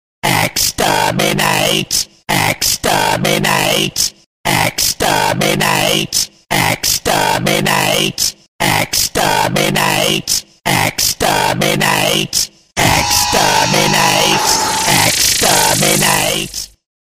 dalek-ringtone_14130.mp3